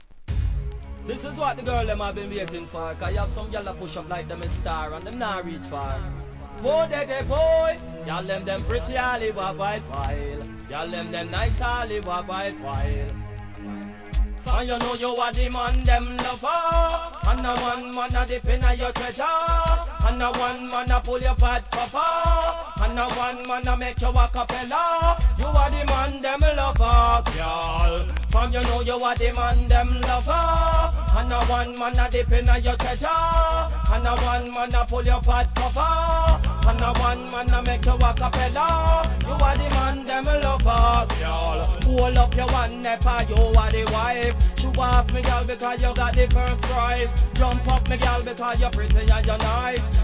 REGGAE
HIP HOP調のRHYTHMです。